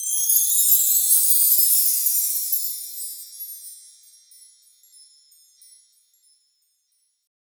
MB Perc (9).wav